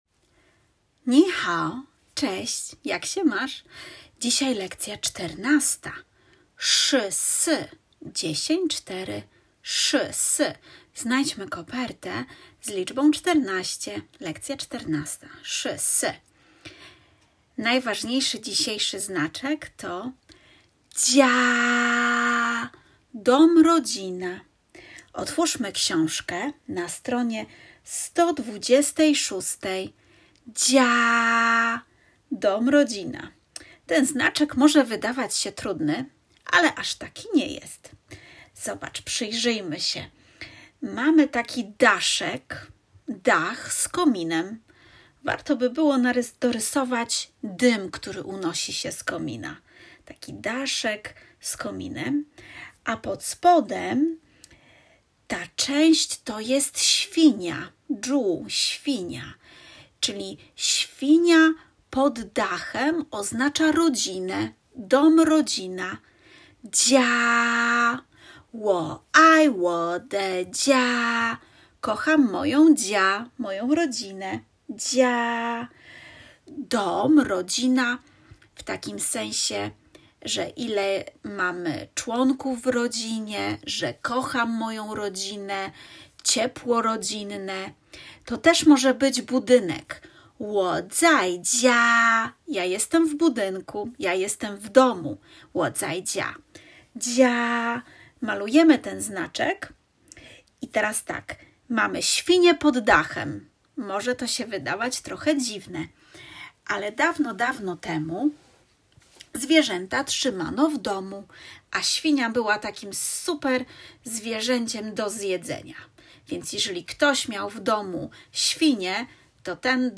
Odsłuchaj przykładową lekcję z pudełka